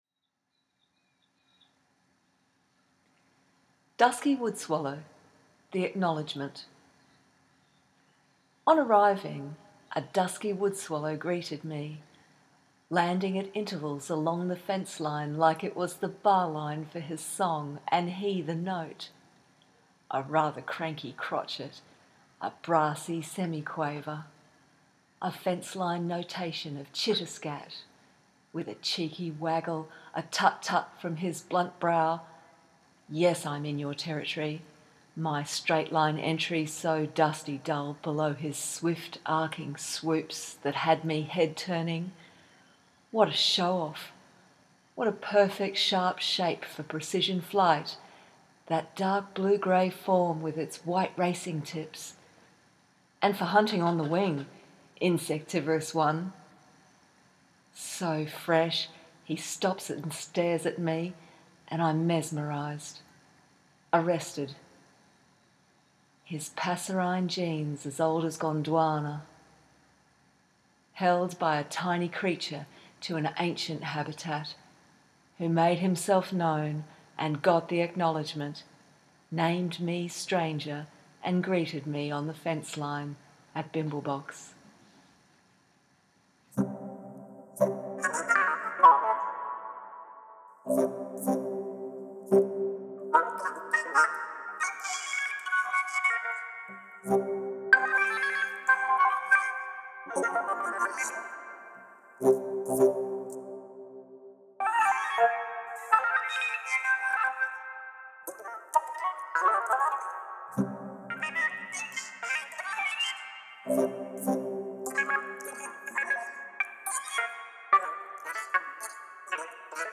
synth vocoder